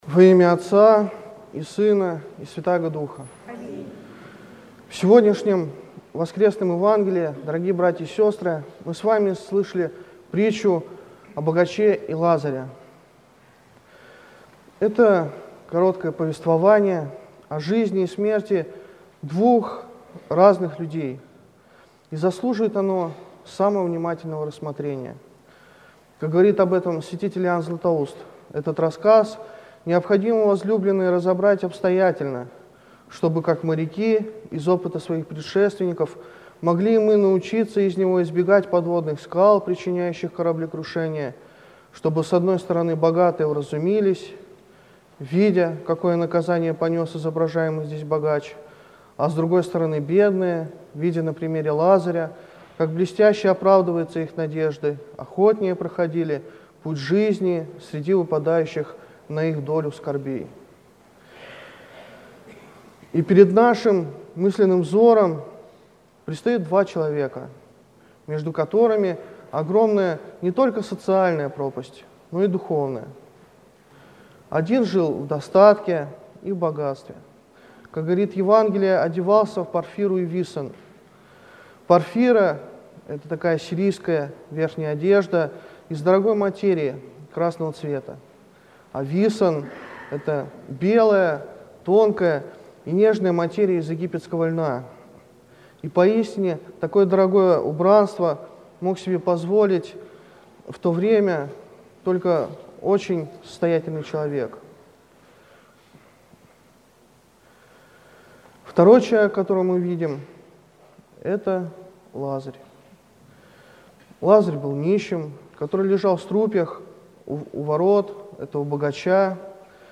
Слово в Неделю 20-ю по Пятидесятнице